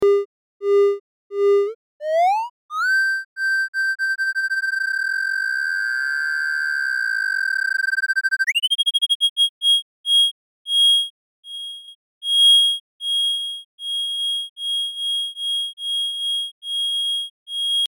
次に、以下のように「exponential」を指定して、テキストではY座標だったのを同じX座標に替えて、上とほぼ同じように画面内を左右に動かしてみた。